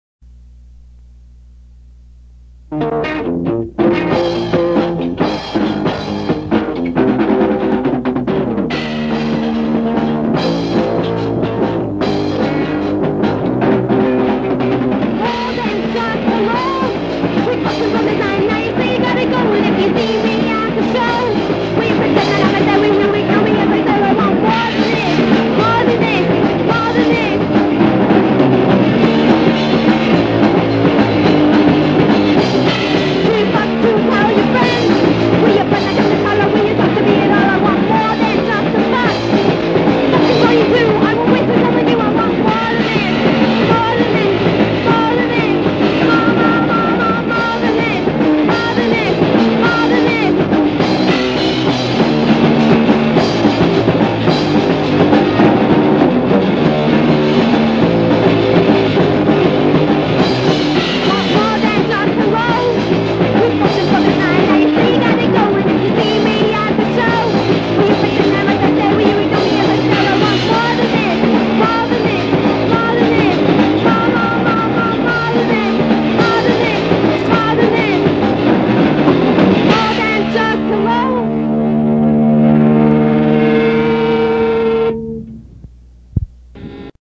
An oldie 90s romantic band I played guitar in temporarily.